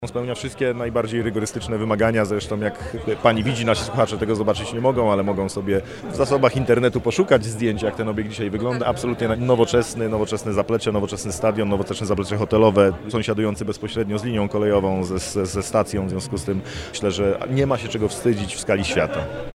-Obiekt spełnia wszelkie olimpijskie wymogi, dodaje minister.